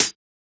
UHH_ElectroHatB_Hit-11.wav